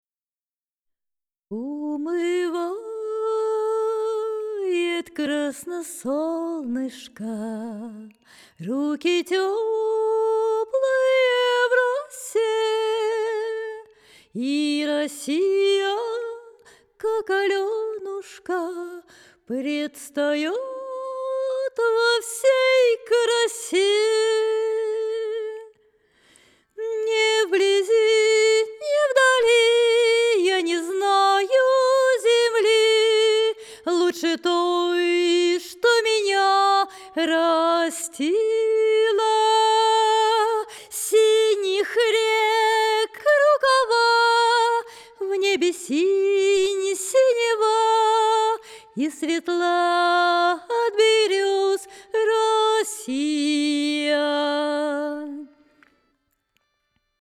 Sennheiser MK4